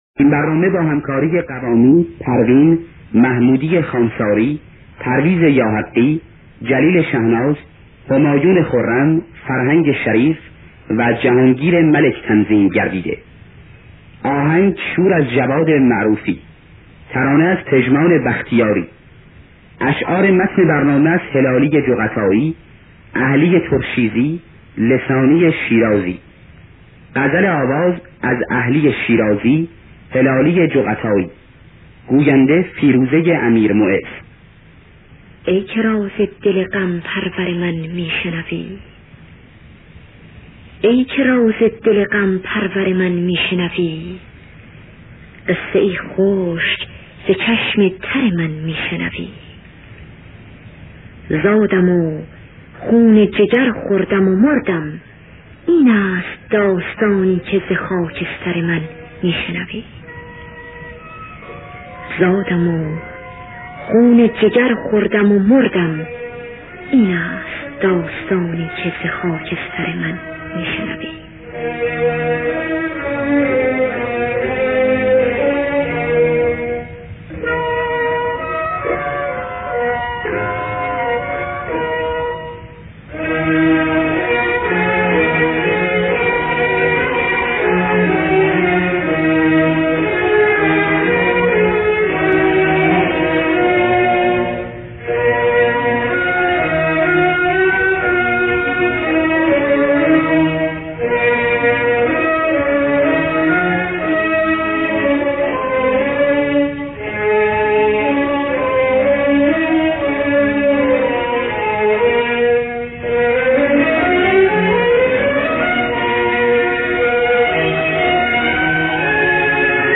دانلود گلهای رنگارنگ ۵۰۳ با صدای پروین، حسین قوامی، محمودی خوانساری در دستگاه شور.